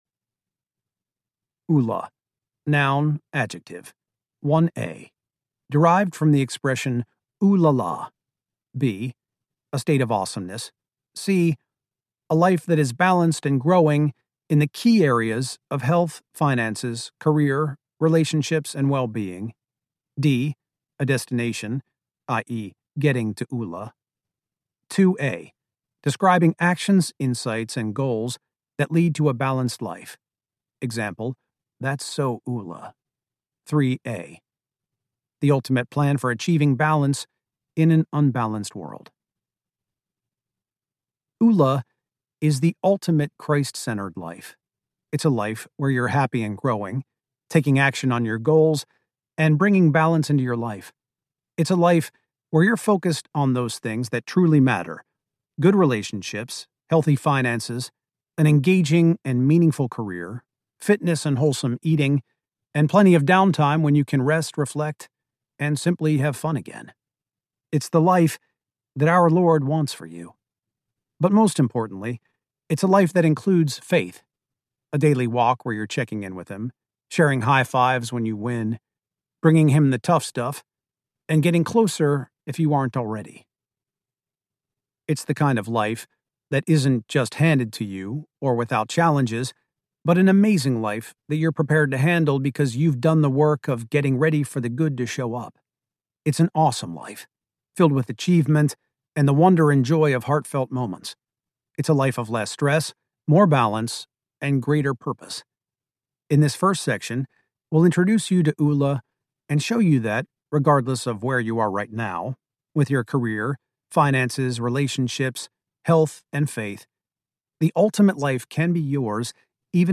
Oola for Christians Audiobook
8.75 Hrs. – Unabridged